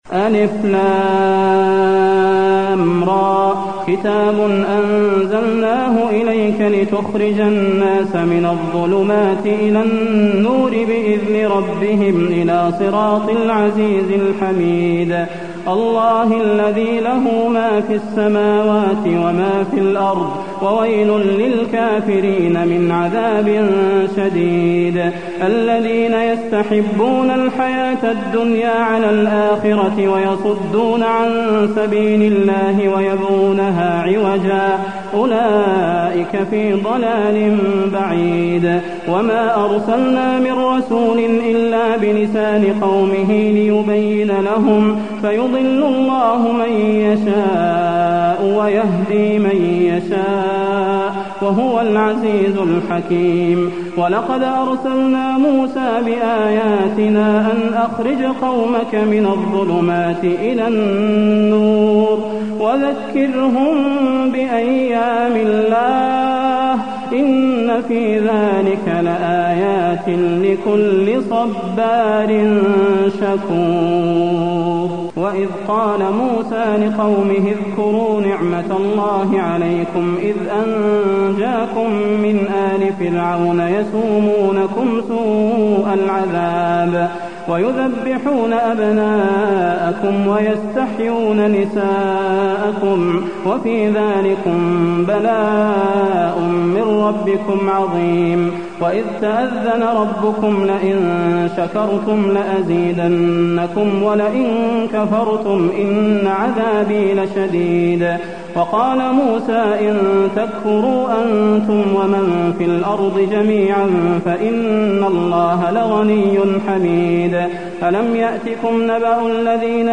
المكان: المسجد النبوي إبراهيم